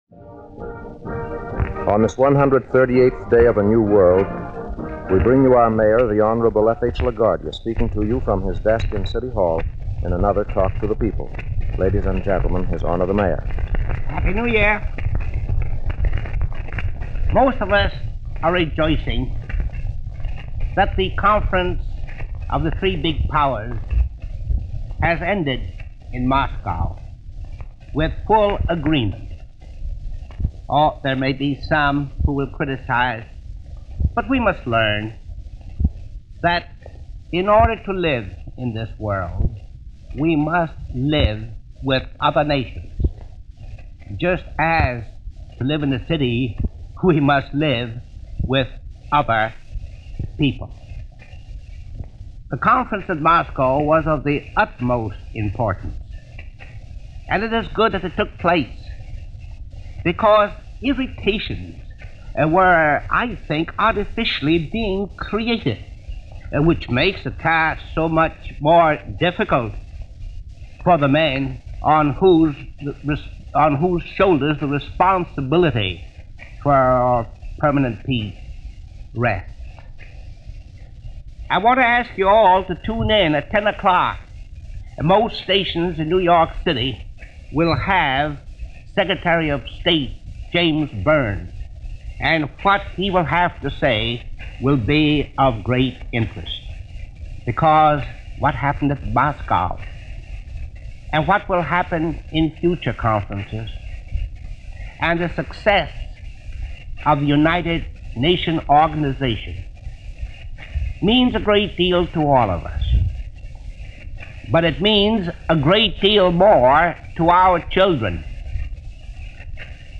Address to New York
Probably the most colorful Mayor New York witnessed in its history, made his end-of-year address on December 30, 1945.
In this address, one of his regular weekly broadcasts given over New York’s own Public Radio station WNYC, Mayor LaGuardia talks about the end of the year, the end of his time in office and the Peace Conference, just concluded in Moscow .